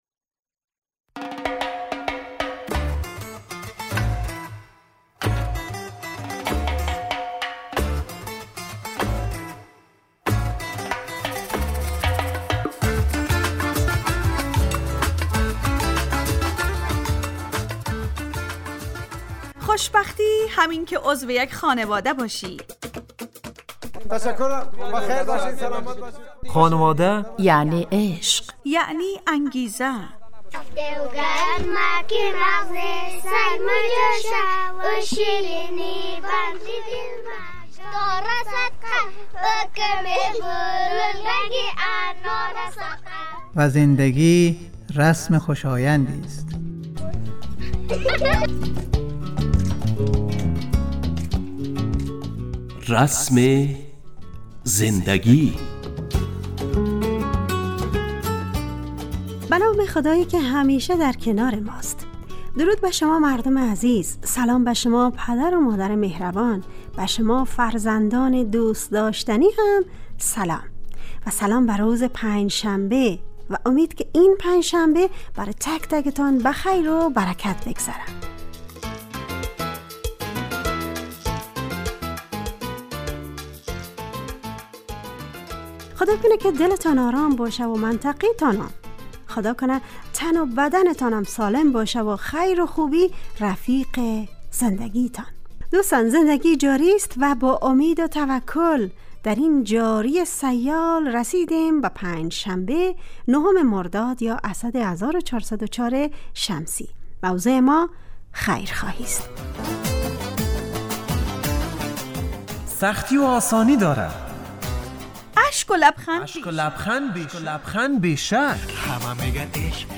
رسم زندگی_ برنامه خانواده رادیو دری